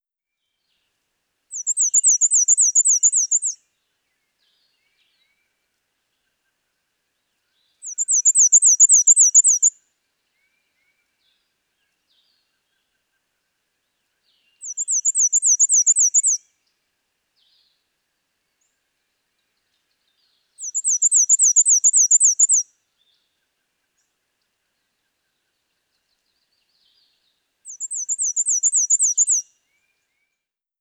ForrestBird1.wav